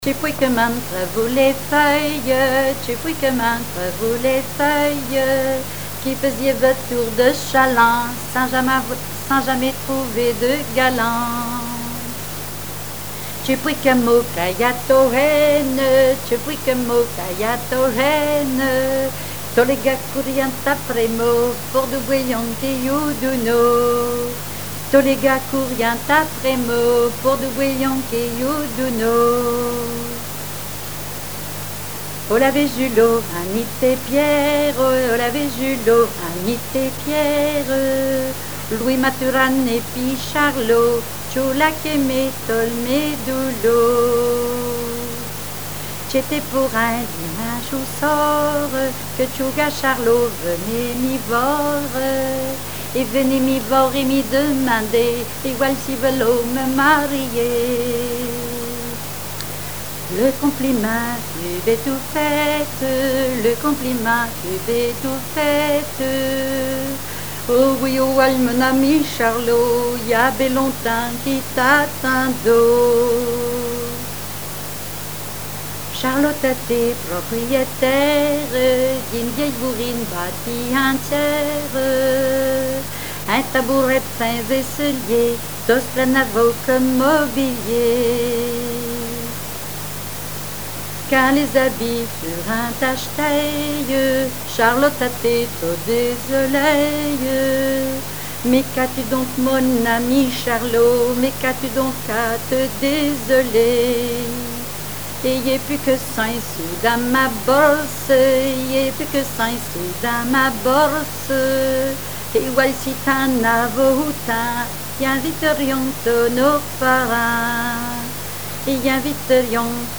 répertoire de chansons populaire et traditionnelles
Pièce musicale inédite